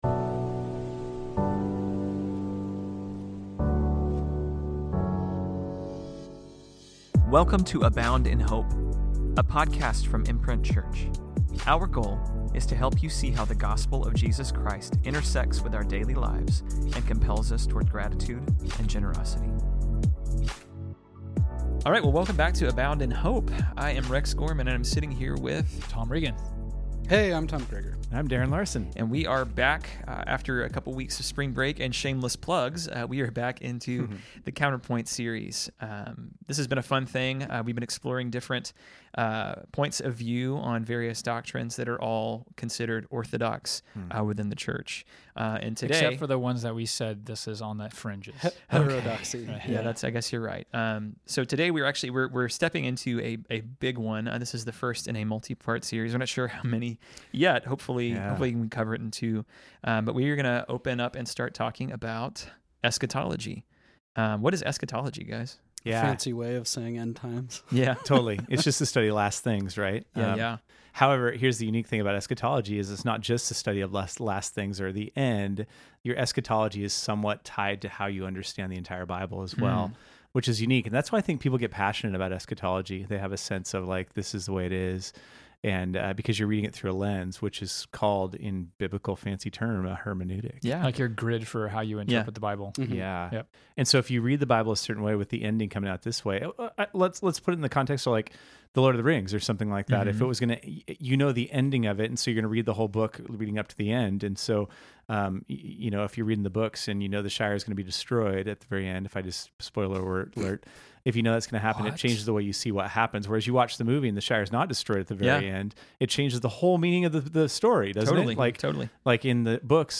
We are back into Counterpoints with a discussion on Eschatology (the end times). How does what we believe about the end affect our Christian walk?